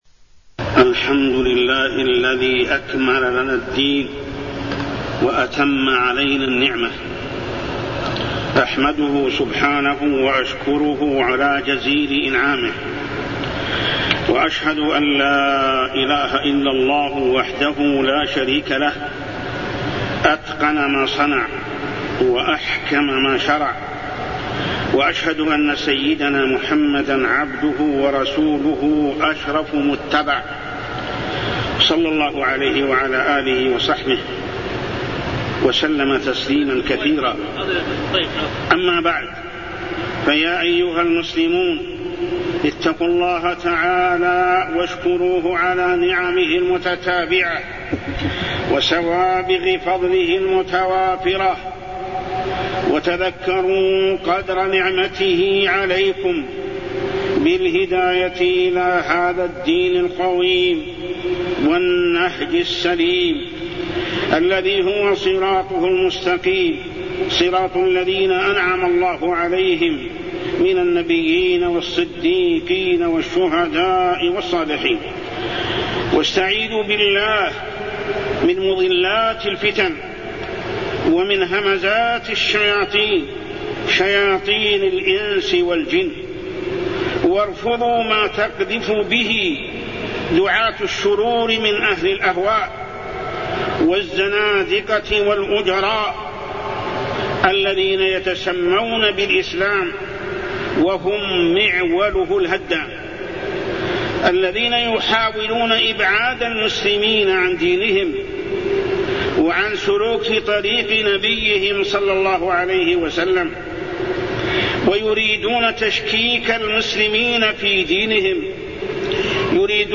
تاريخ النشر ٨ صفر ١٤٢١ هـ المكان: المسجد الحرام الشيخ: محمد بن عبد الله السبيل محمد بن عبد الله السبيل صلاح الإسلام لكل زمان ومكان